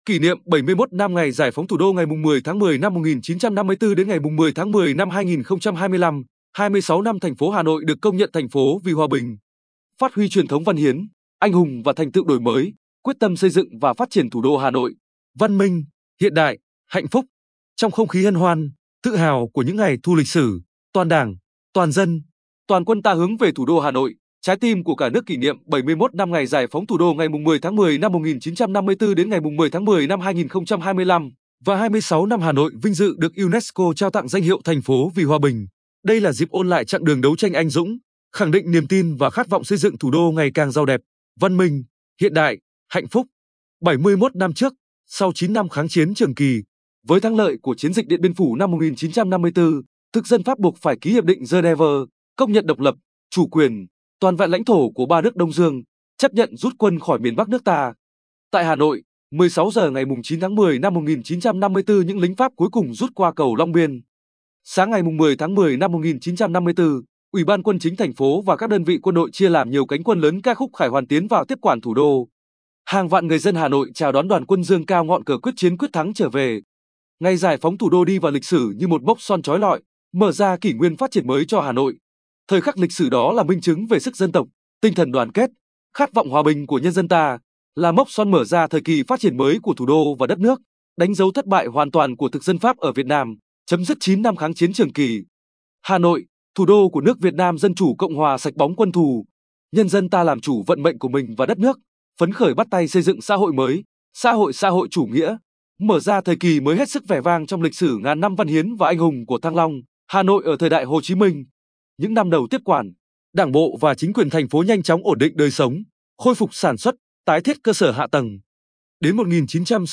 Bài phát thanh 10.10.2025